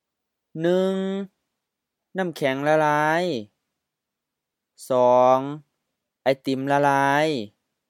na:m-khɛŋ HF-M
la-la:i H-HR